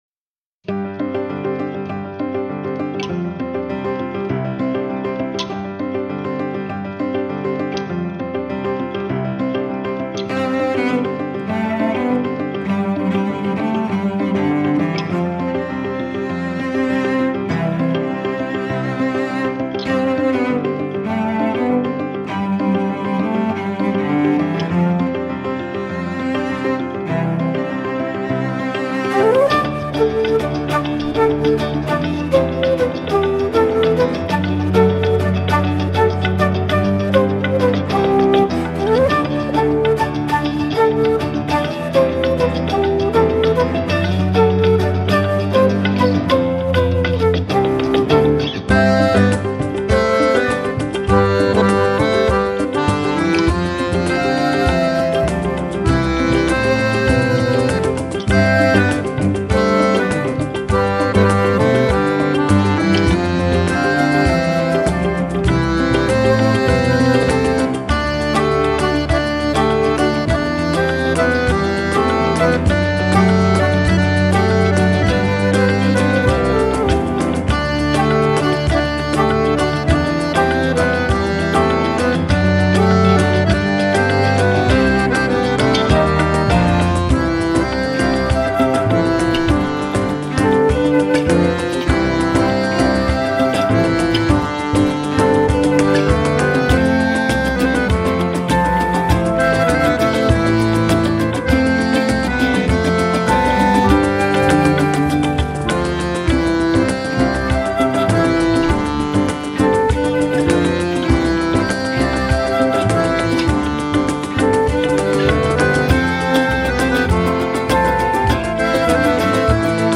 Una musica da camera che fa battere il piede